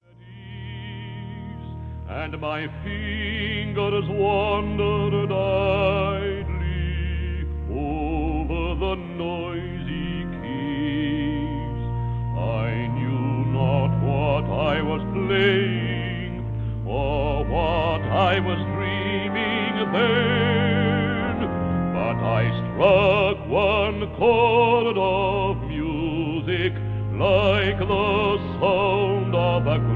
at the organ in a 1934 recording